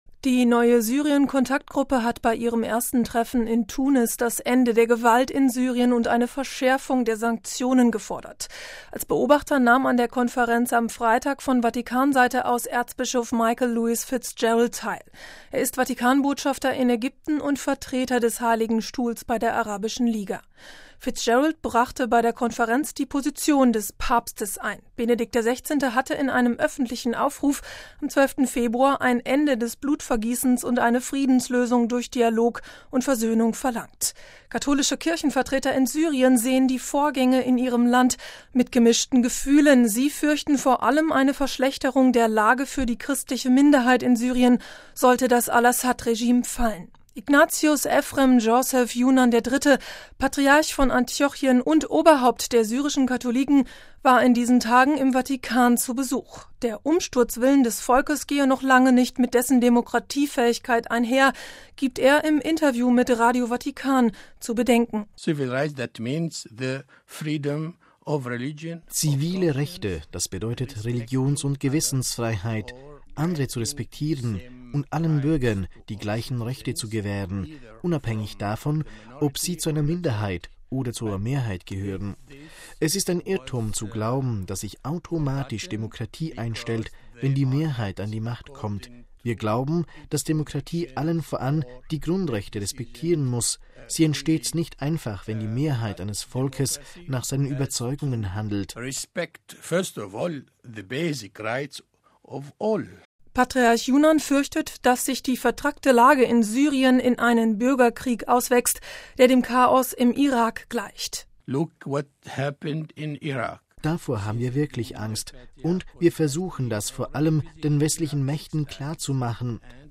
Der Umsturzwillen des Volkes gehe noch lange nicht mit dessen Demokratiefähigkeit einher, gibt er im Interview mit Radio Vatikan zu bedenken: